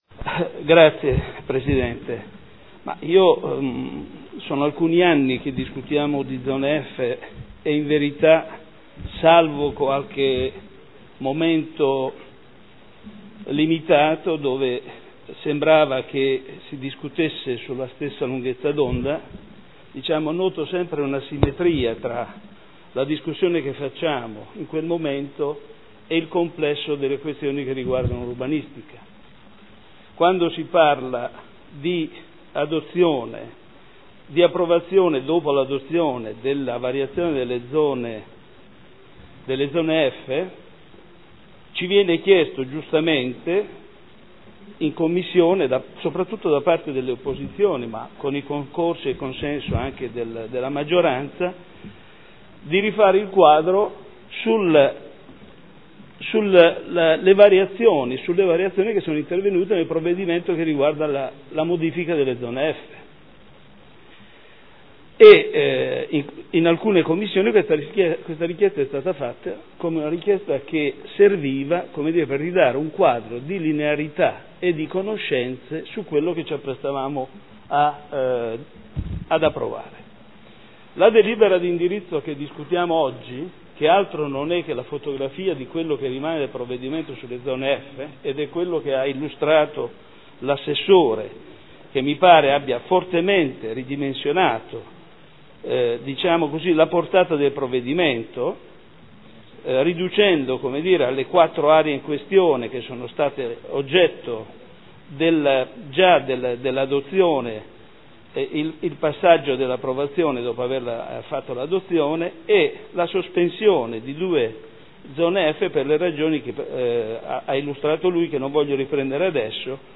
Seduta del 15/07/2013 Dibattito. Perequazione nelle Zone F – Revisione dell’art. 16.7 bis POC